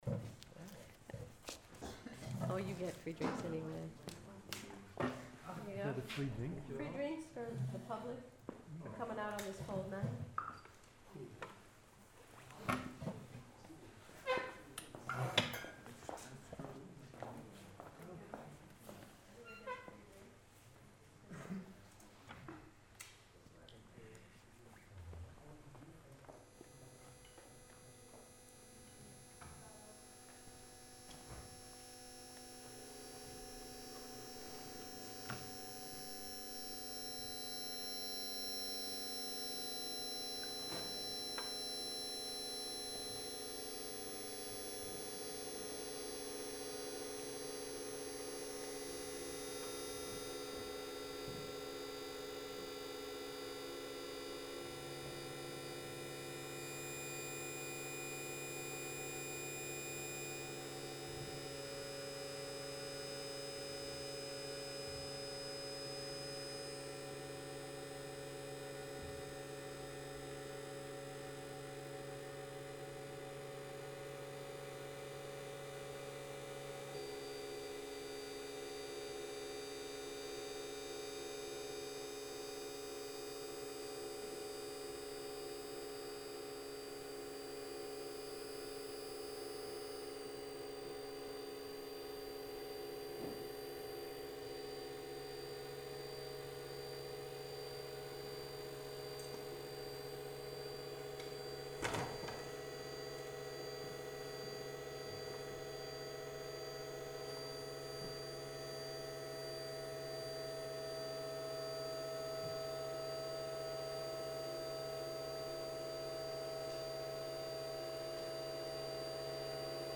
BAK, Utrecht, NL - June 12-24